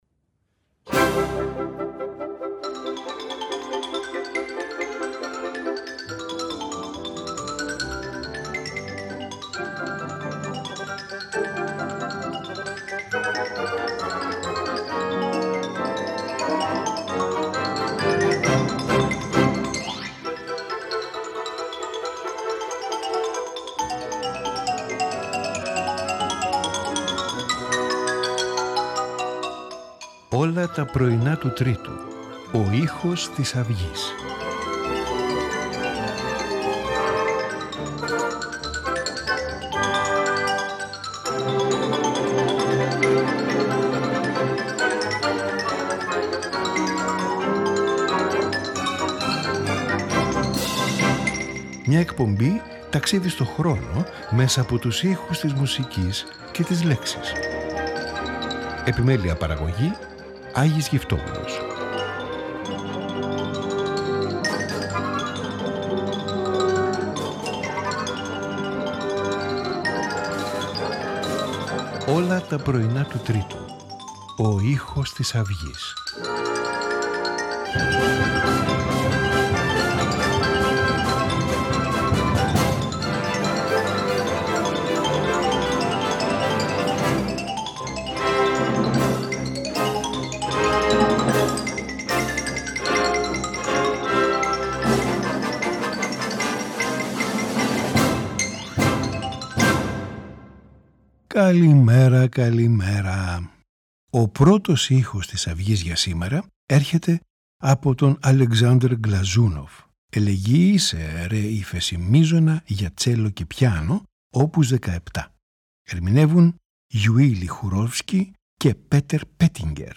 cello and piano
Piano concerto